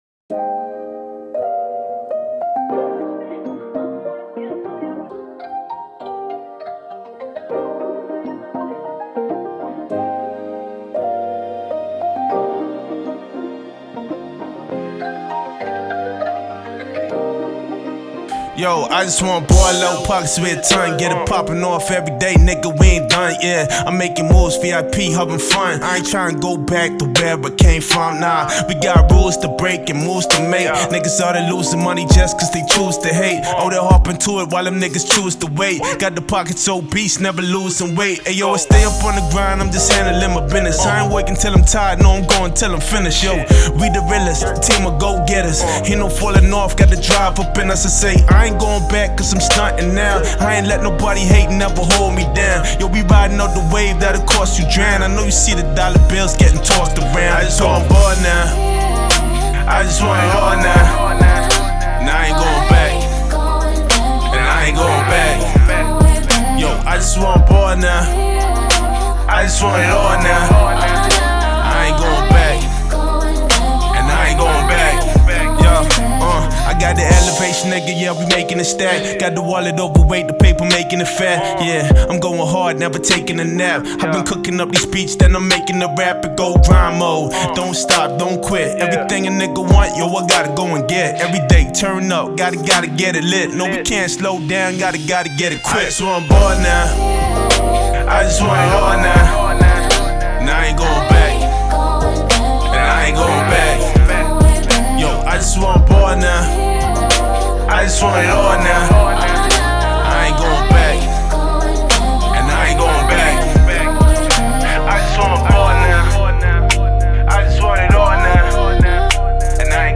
This is a mp3 acapella file and does not include stems